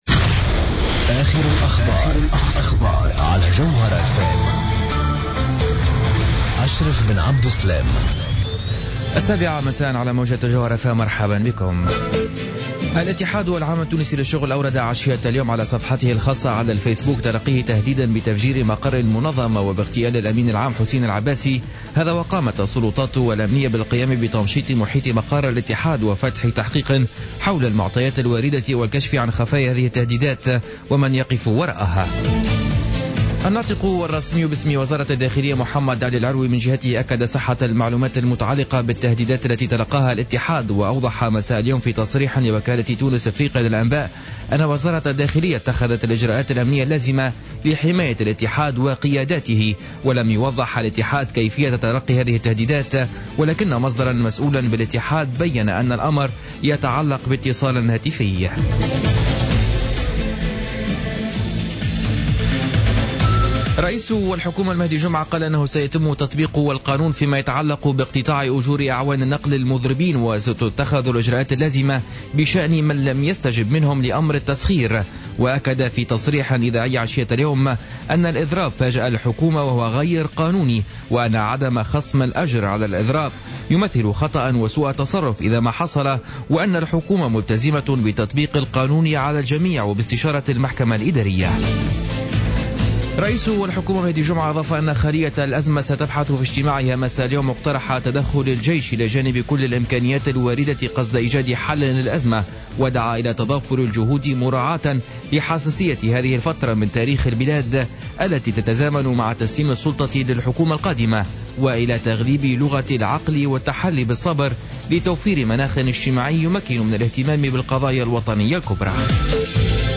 نشرة اخبار السابعة مساء ليوم الخميس 15-01-15